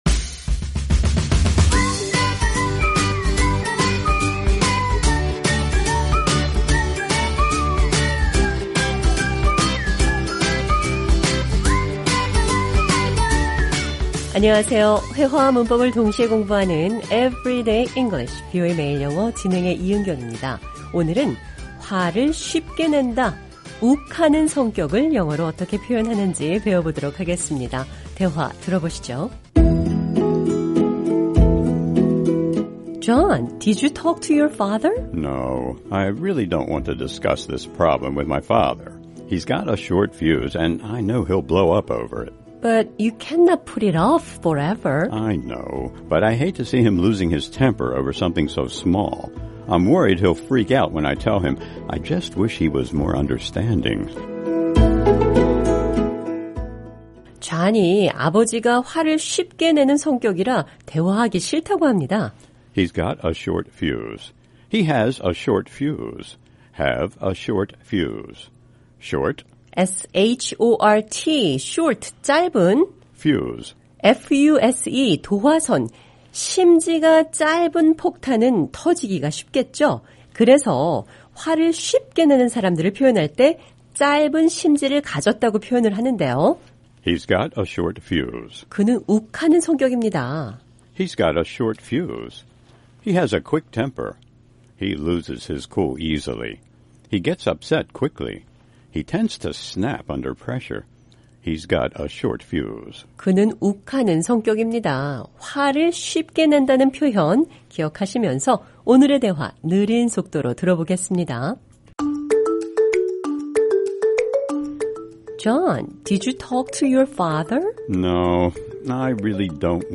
오늘은 화를 쉽게낸다, 욱하는 성격을 영어로 어떻게 표현하는지 배워보겠습니다. 대화 들어보시죠.